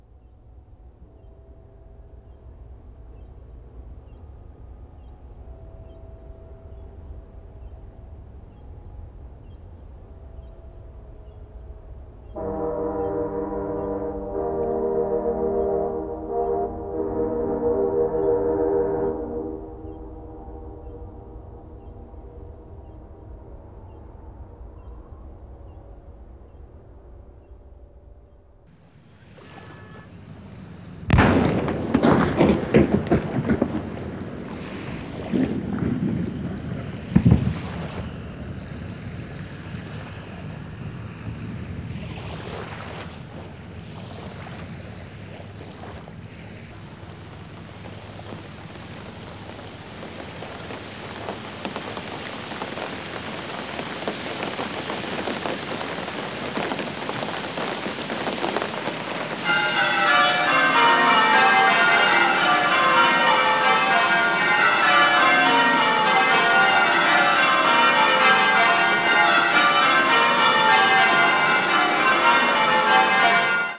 (O Canada horn, Nine O'Clock Gun, Holy Rosary Cathedral bells)